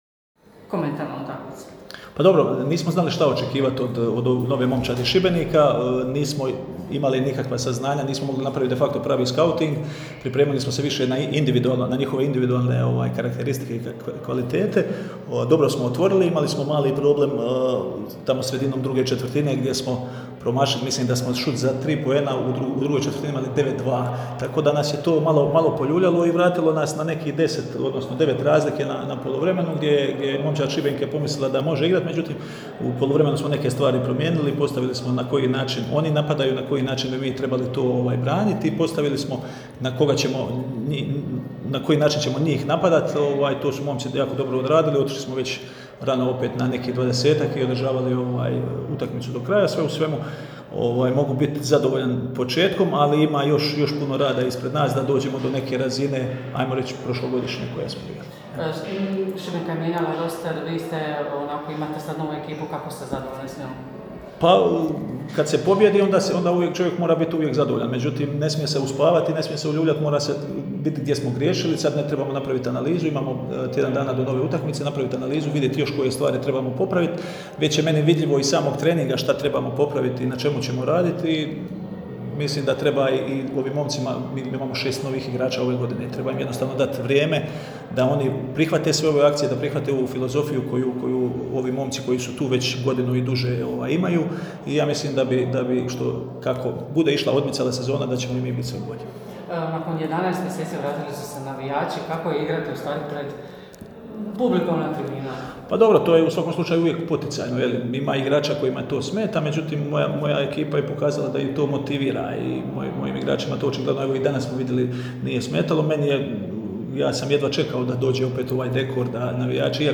IZJAVE: